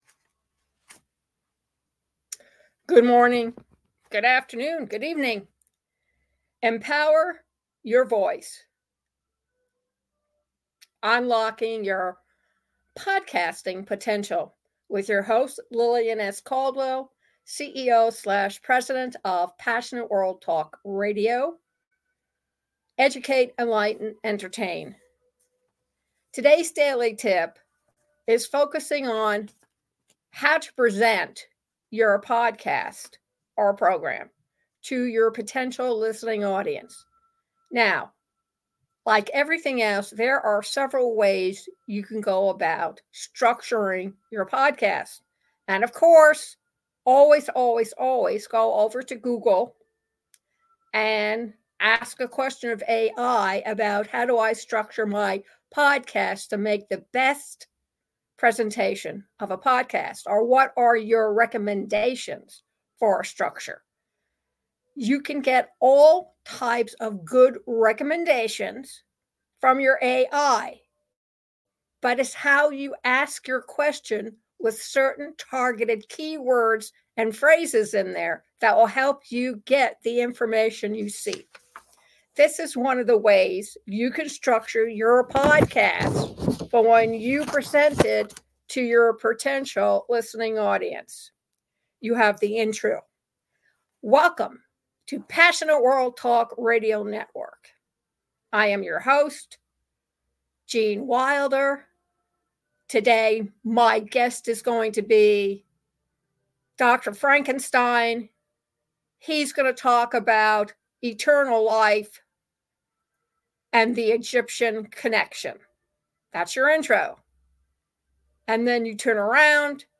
Clarify key points, offer context, and enhance understanding as you guide listeners through each section of your content.